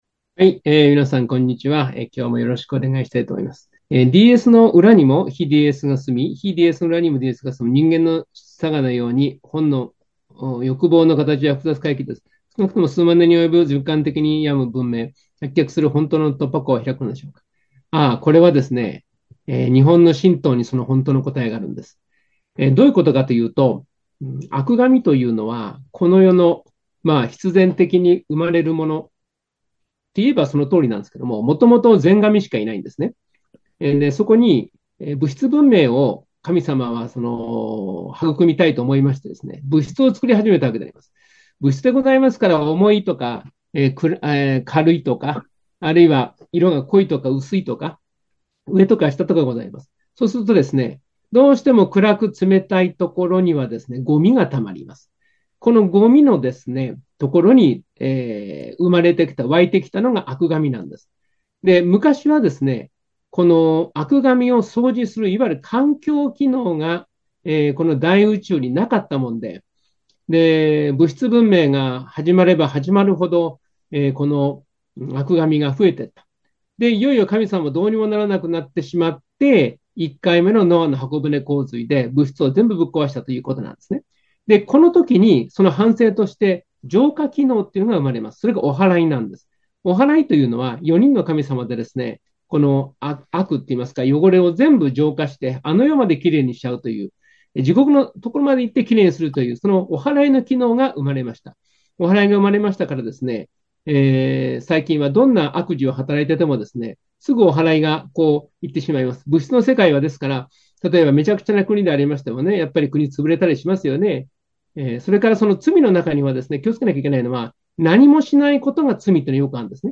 第100回NSP時局ならびに日本再生戦略講演会 / 令和6年11月質疑応答